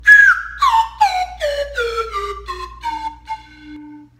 embolo_baixa_a_saltos.mp3